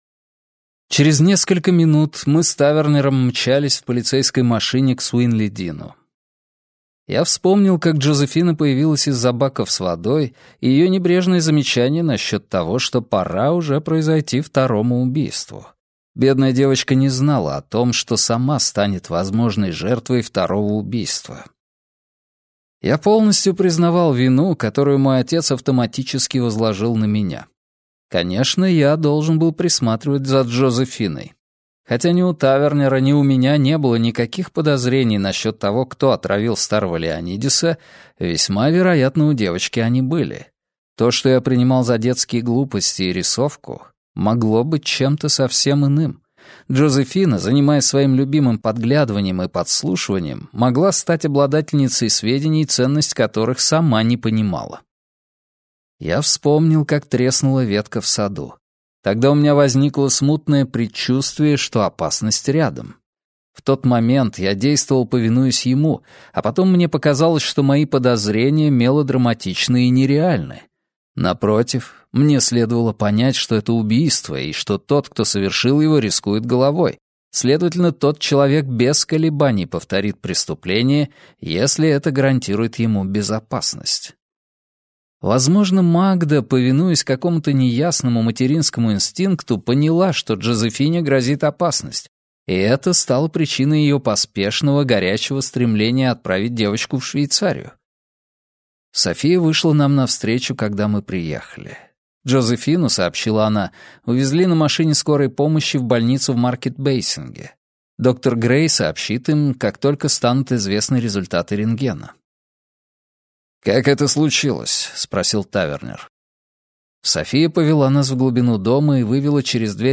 Аудиокнига Скрюченный домишко - купить, скачать и слушать онлайн | КнигоПоиск